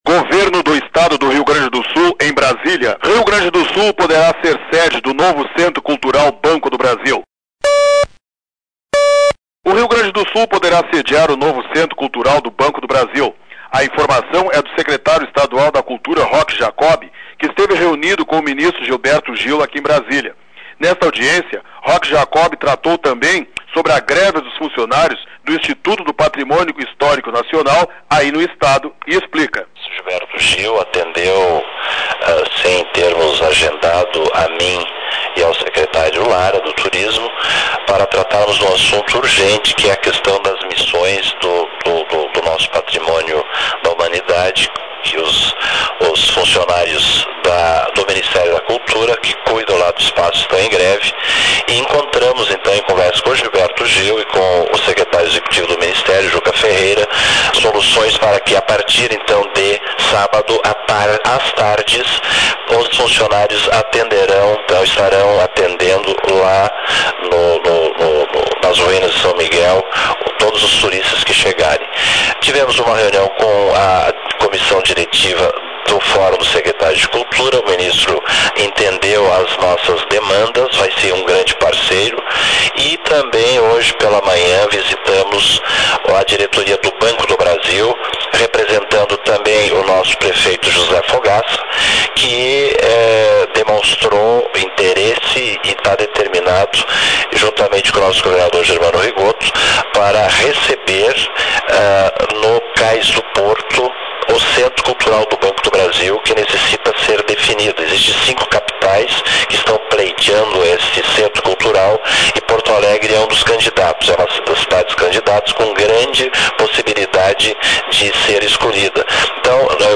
Sonora: secretário da Cultura do Rio Grande do Sul, Roque Jacoby, em Brasília.Local: Brasília-DFDuração: 00:02:30